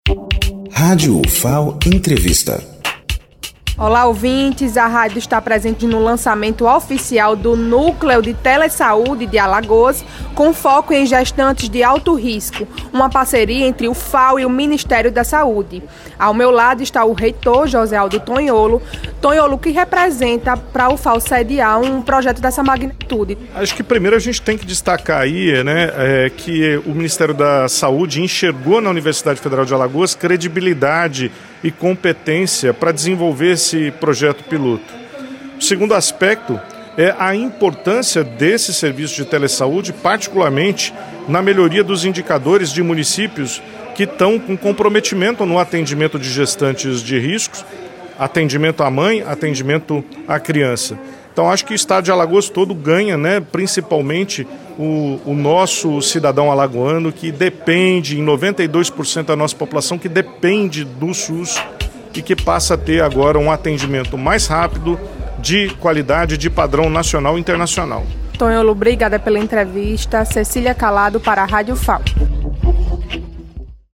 Entrevista com Josealdo Tonholo, reitor da universidade.